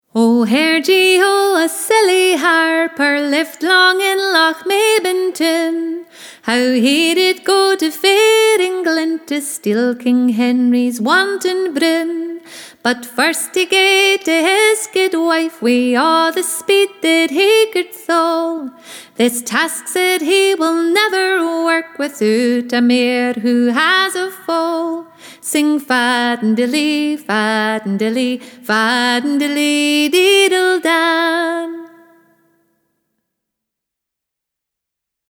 Scottish Music Download The Lochmaben Harper MP3